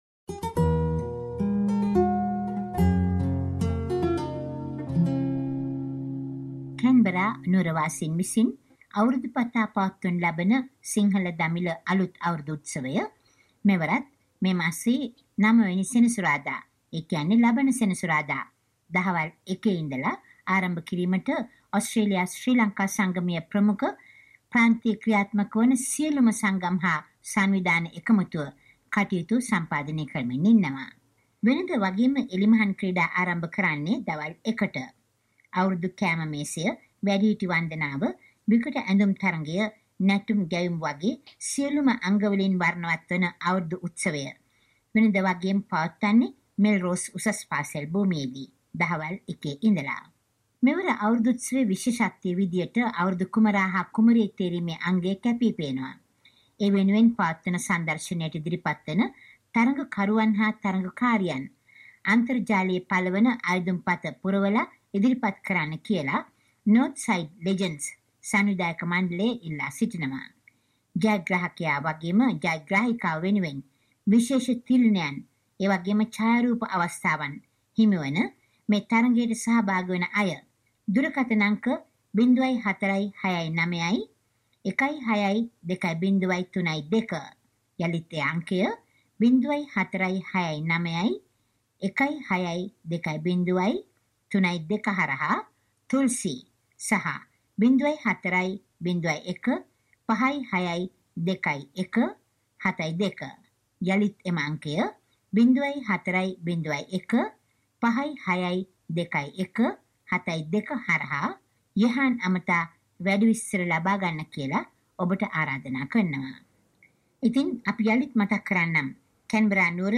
කැන්බරා නුවර 2022 සිංහල අලුත් අවුරුදු සැමරුම පිළිබඳව ගෙන එන වාර්තාව.